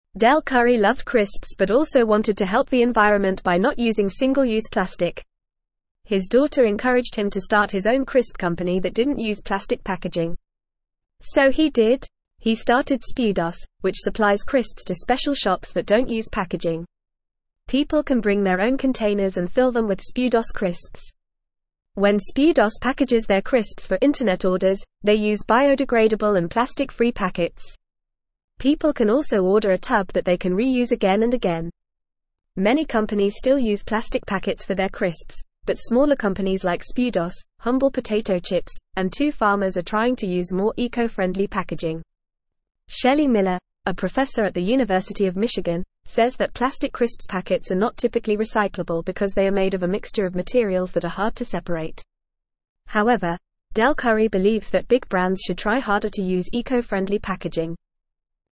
English-Newsroom-Beginner-FAST-Reading-No-Plastic-Crisp-Packets-for-Spudos.mp3